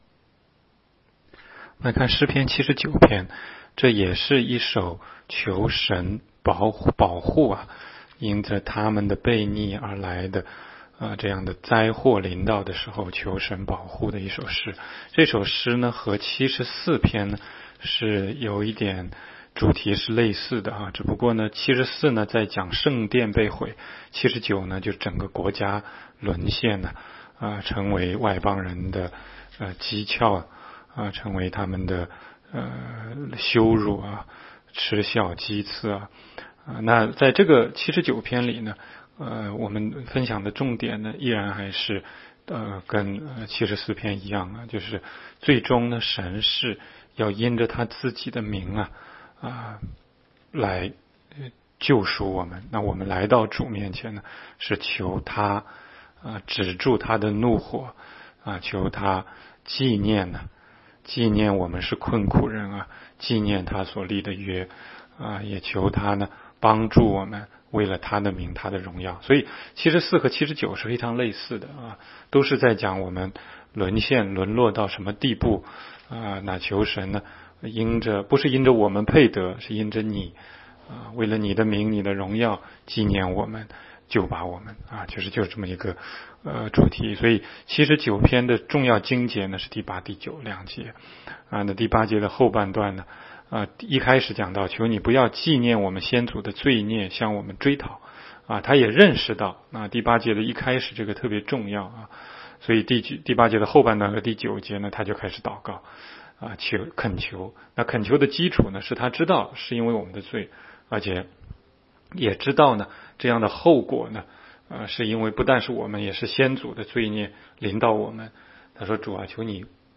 16街讲道录音 - 每日读经-《诗篇》79章